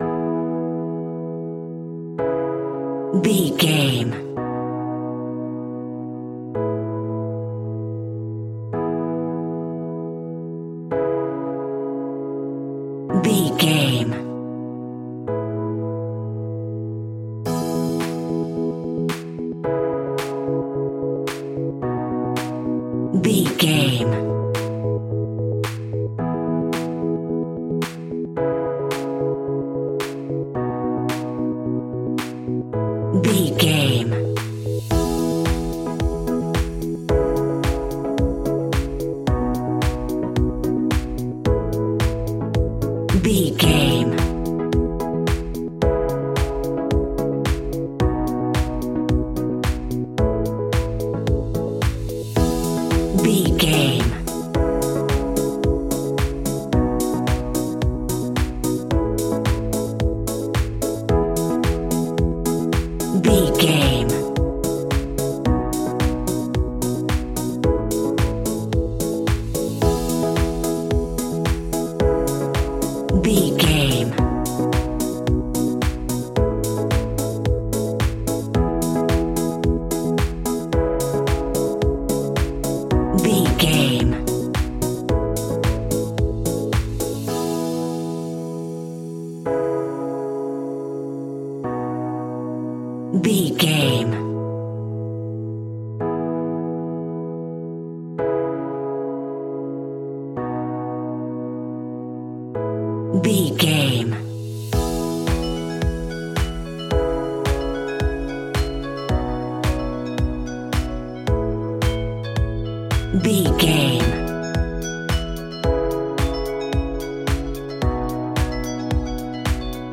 Ionian/Major
peaceful
calm
joyful
hopeful
electric piano
synthesiser
drums
strings
electro house
funky house
synth bass